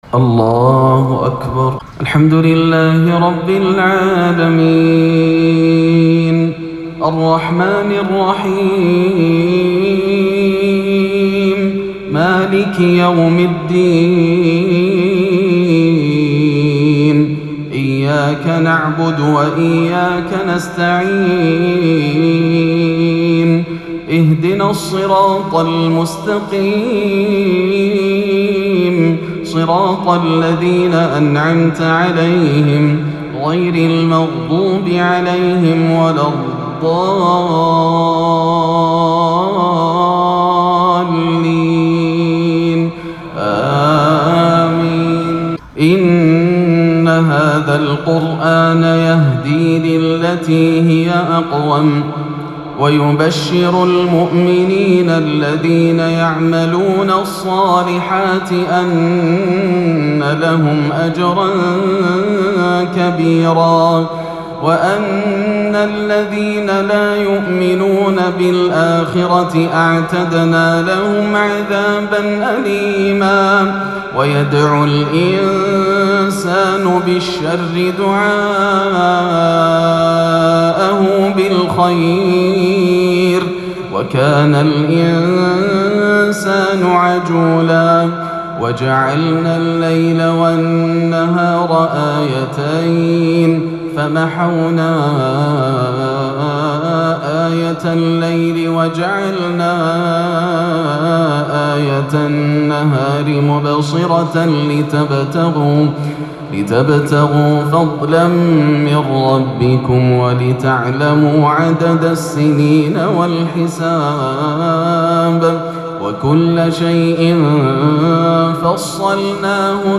( وَقَضى رَبُّكَ أَلّا تَعبُدوا إِلّا إِيّاهُ ) تلاوة خاشعة - من جامع سيد الشهداء - عشاء الإثنين 7-8 > عام 1439 > الفروض - تلاوات ياسر الدوسري